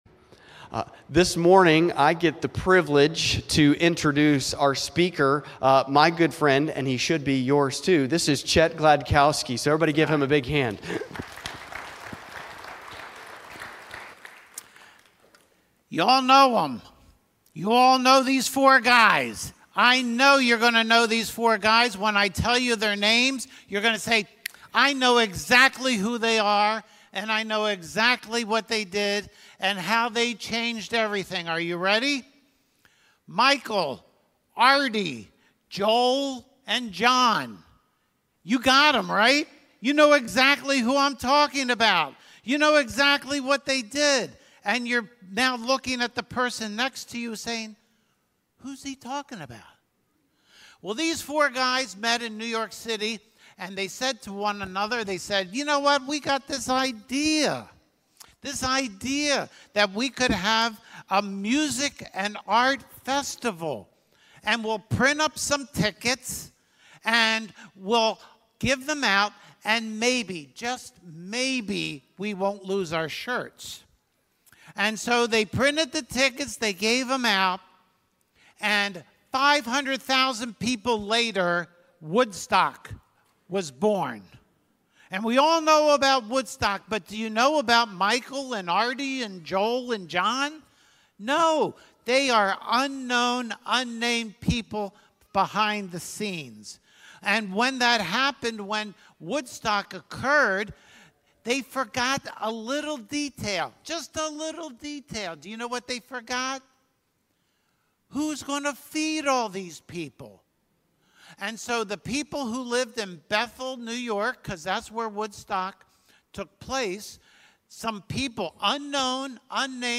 The Great Unknowns: Christ Community Church, Sunday Morning Service, July 23, 2023